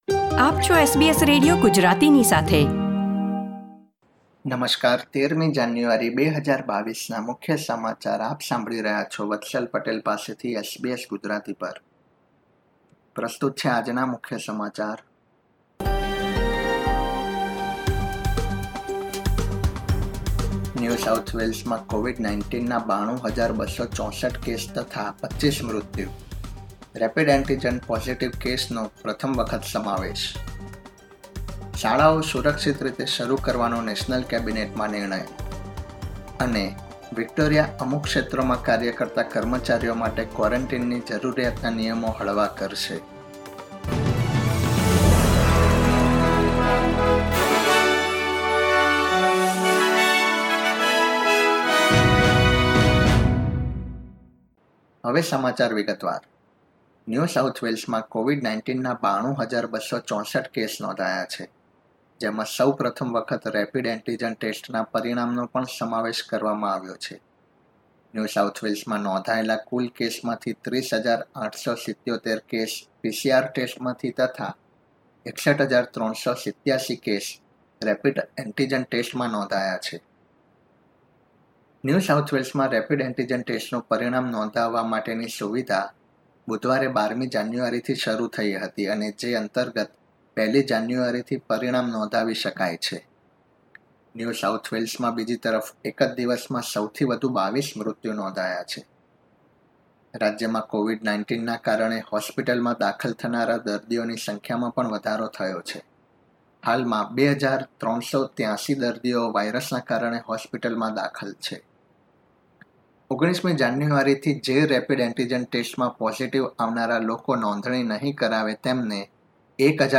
SBS Gujarati News Bulletin 13 January 2022